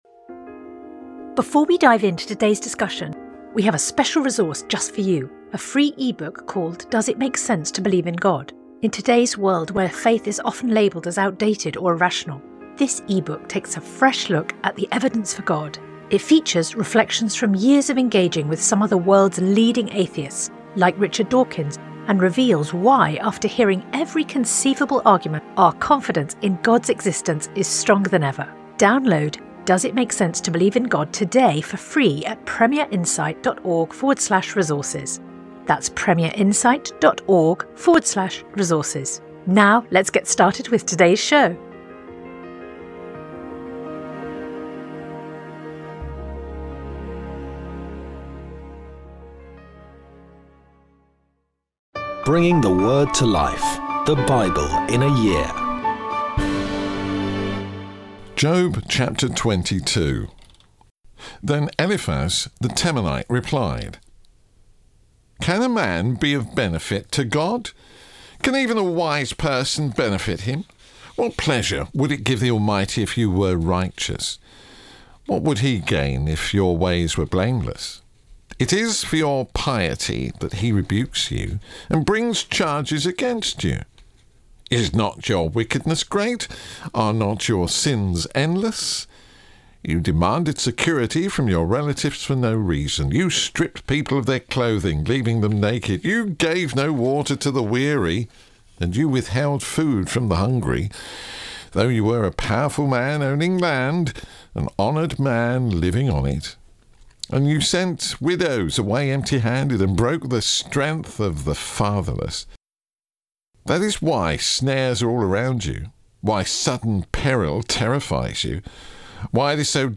Today’s reading comes from Job 22-24; Colossians 2 Sponsored ad Sponsored ad